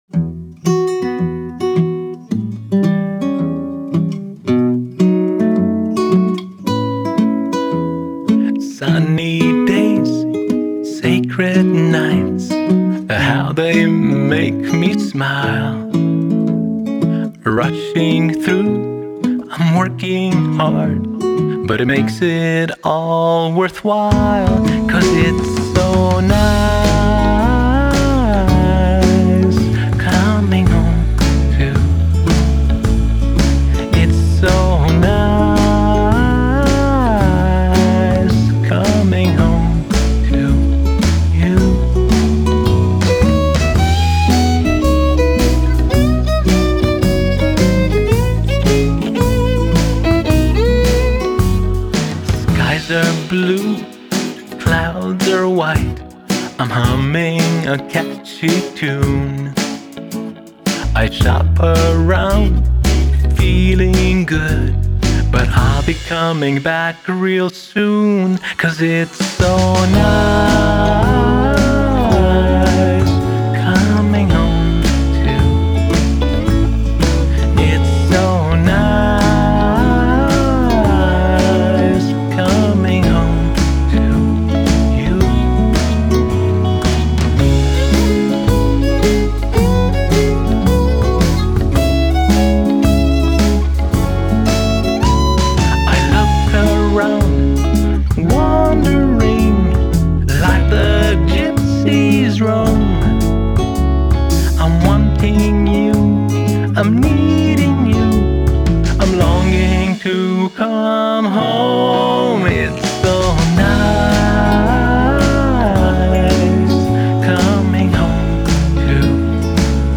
Genre: Pop Rock, Adult Alternative Pop/Rock